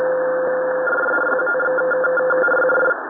FSK-2, разнос 900 Гц, скорость 38 Бод
FSK-2 разнос 900 Гц скорость где-то 38 Бод (может 38,5); принято на 7982 кГц. Попадается достаточно часто - всегда вот такая короткая посылочка и больше ничего.